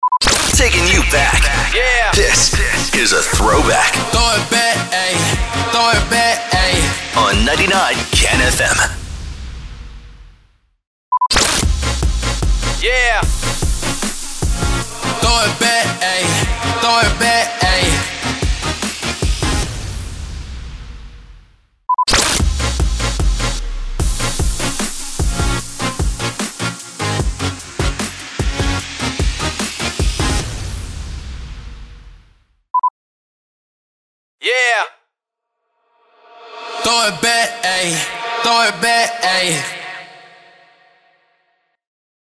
308 – SWEEPER – THROWBACK
308-SWEEPER-THROWBACK.mp3